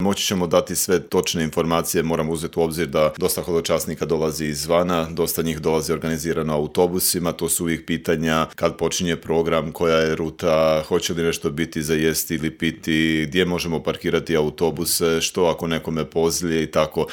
Cijeli intervju možete pronaći na YouTube kanalu Media servisa.